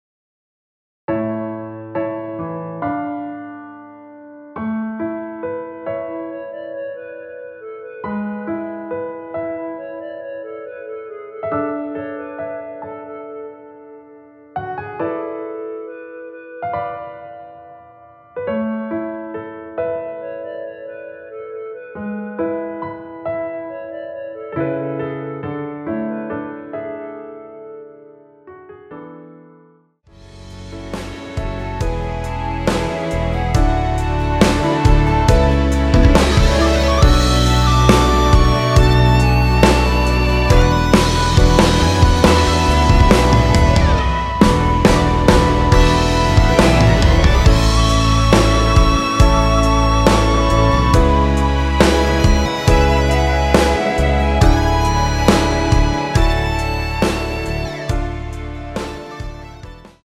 노래 들어가기 쉽게 전주 1마디 만들어 놓았습니다.(미리듣기 확인)
원키에서(+5)올린 멜로디 포함된 MR입니다.
앞부분30초, 뒷부분30초씩 편집해서 올려 드리고 있습니다.